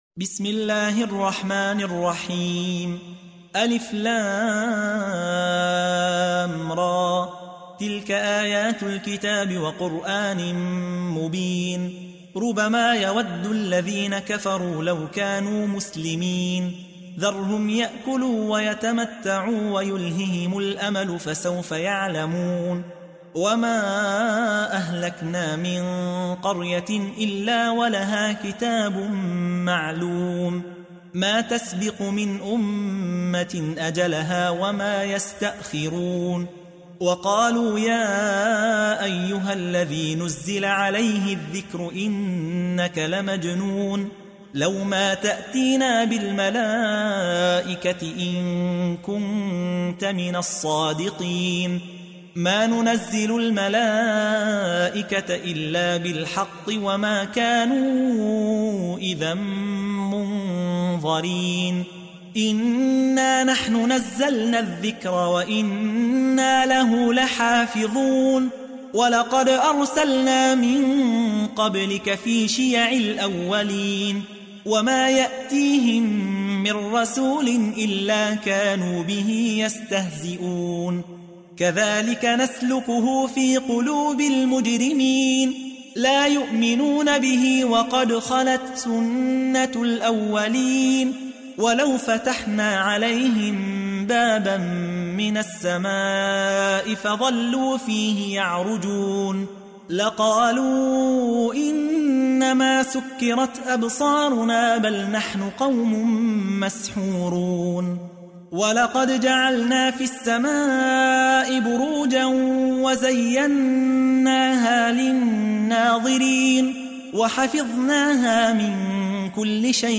15. سورة الحجر / القارئ